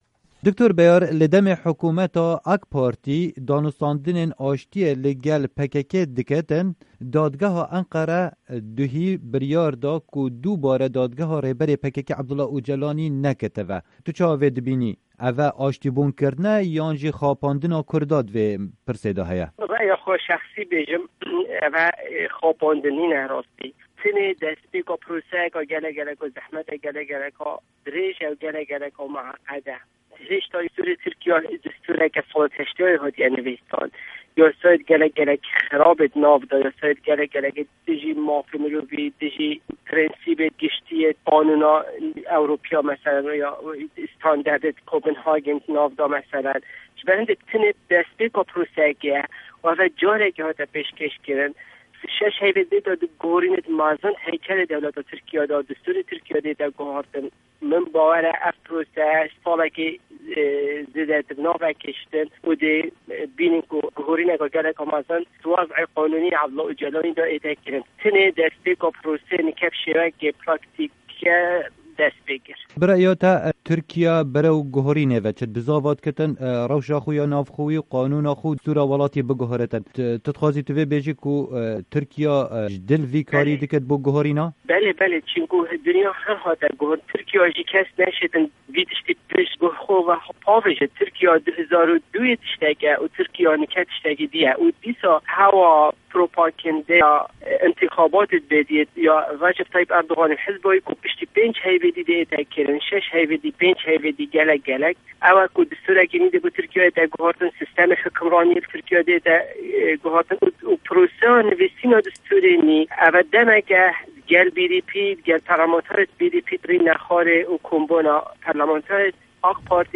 وتوێژ